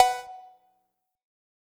TS Perc_6.wav